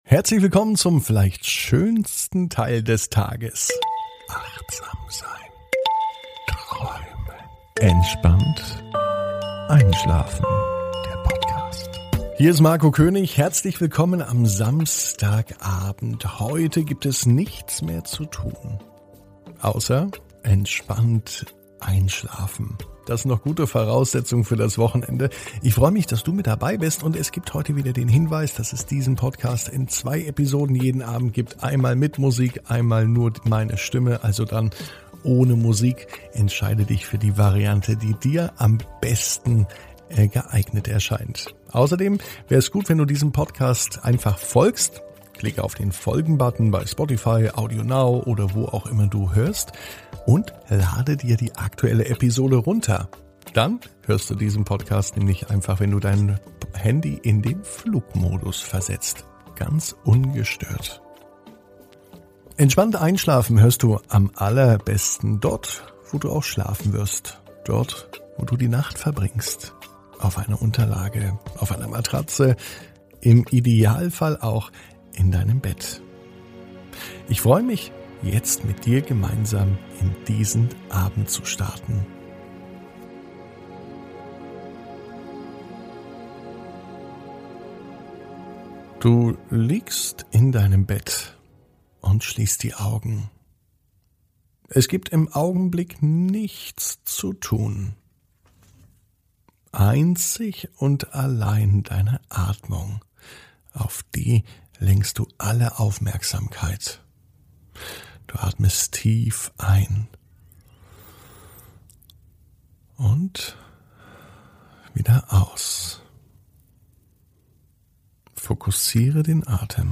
(ohne Musik) Entspannt einschlafen am Samstag, 08.05.21 ~ Entspannt einschlafen - Meditation & Achtsamkeit für die Nacht Podcast